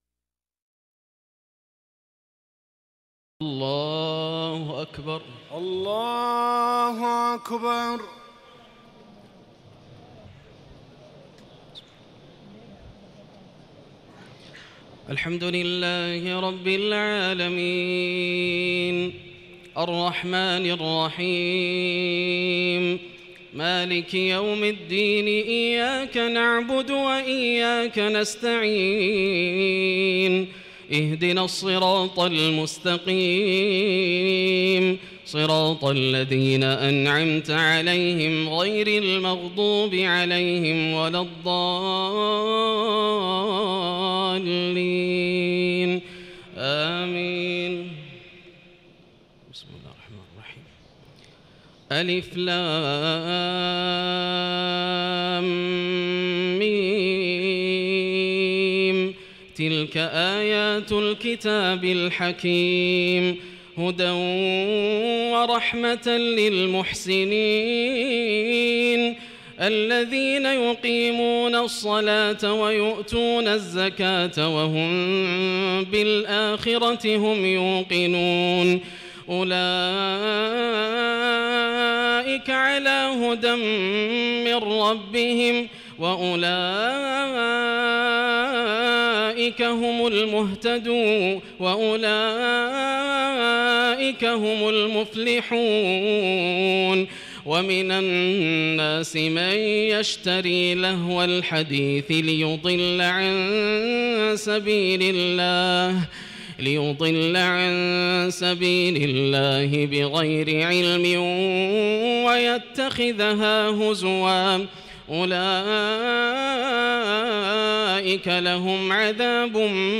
تراويح ليلة 30 رمضان 1437هـ سورتي لقمان و السجدة Taraweeh 30 st night Ramadan 1437H from Surah Luqman and As-Sajda > تراويح الحرم المكي عام 1437 🕋 > التراويح - تلاوات الحرمين